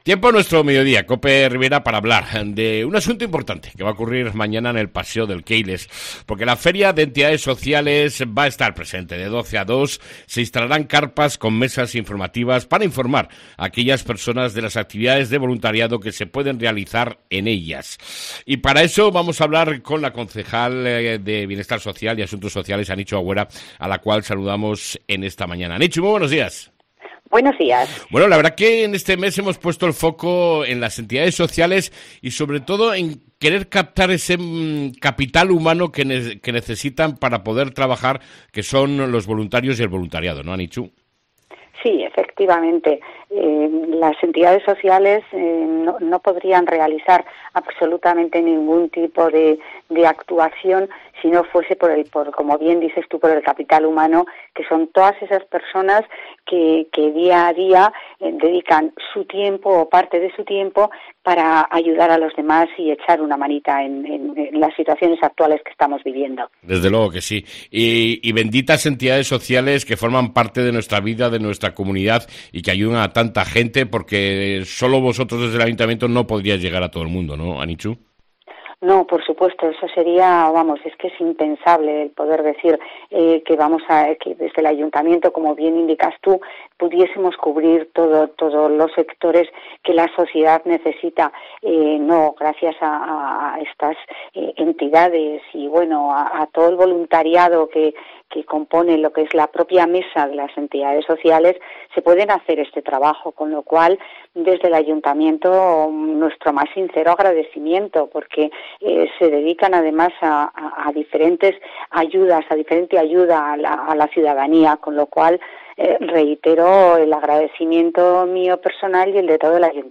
ENTREVISTA CON LA CONCEJAL DE BIENESTAR SOCIAL Y MUJER, ANICHU AGÜERA